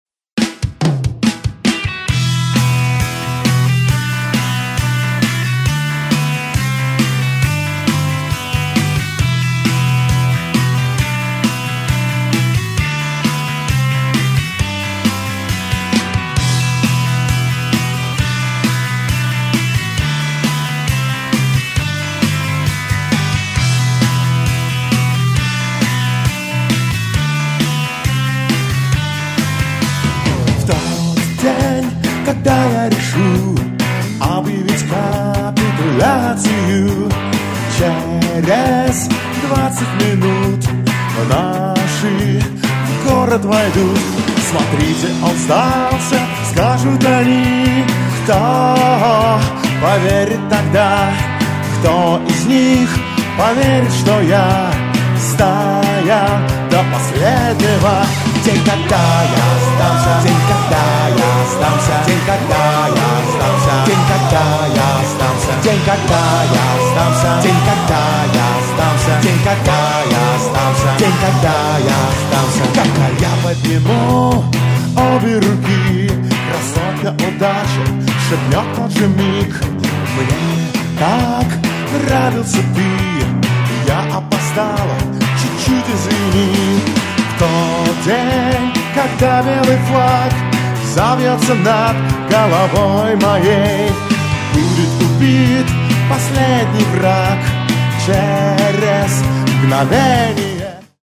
(demo)
еще не финальная версия, не полностью